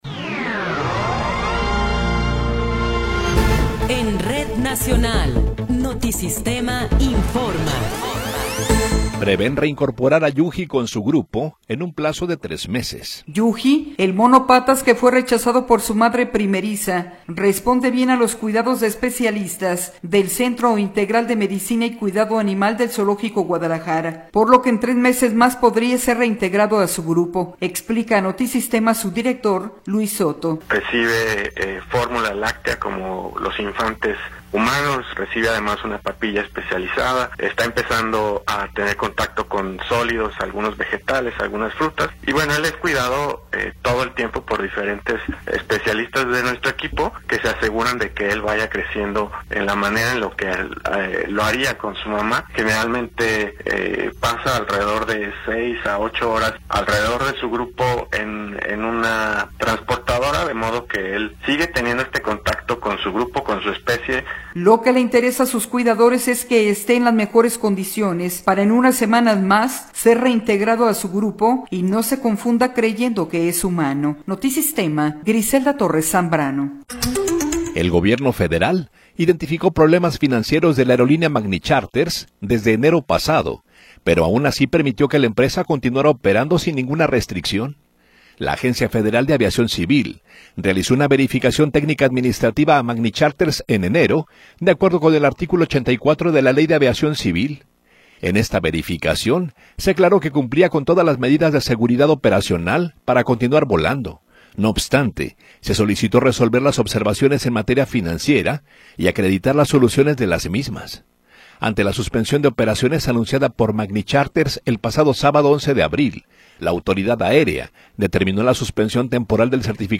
Noticiero 11 hrs. – 15 de Abril de 2026